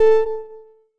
beep1.wav